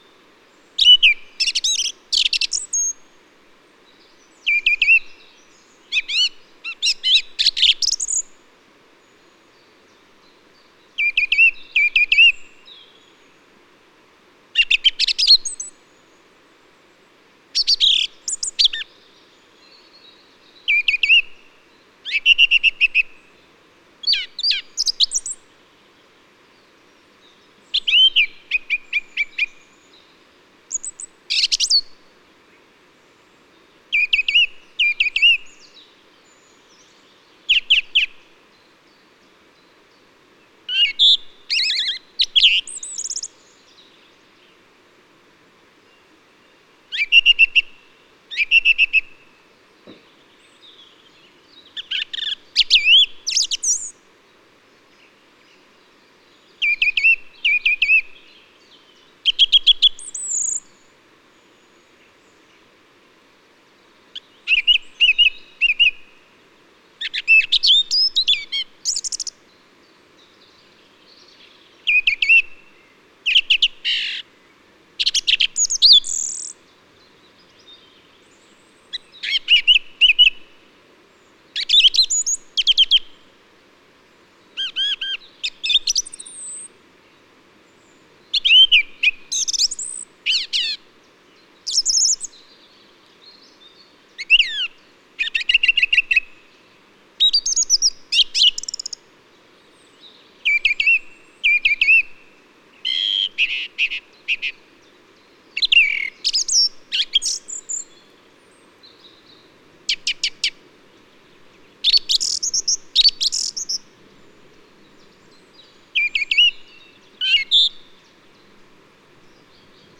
Singdrossel
Hören Sie sich hier die Stimme der Singdrossel an.
Singdrossel.mp3